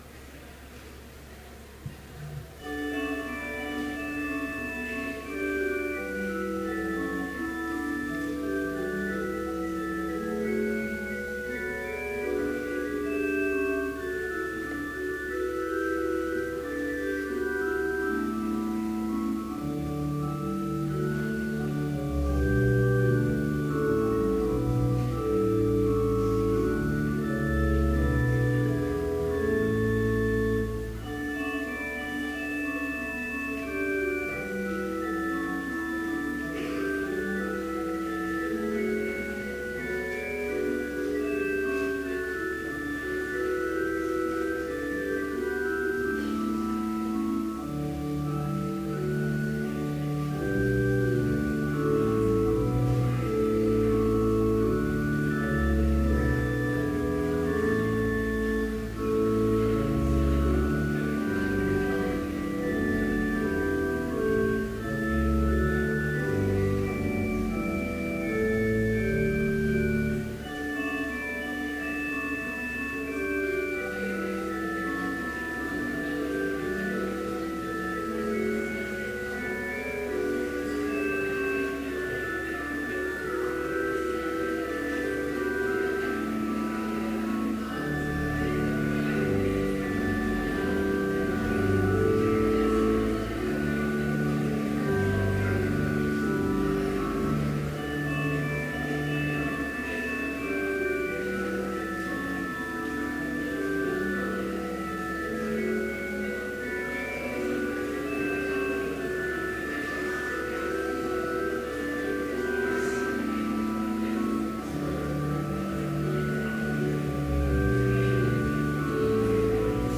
Complete service audio for Chapel - February 17, 2015